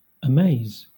Ääntäminen
Southern England
RP : IPA : /əˈmeɪz/